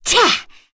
Cecilia-Vox_Attack2_kr.wav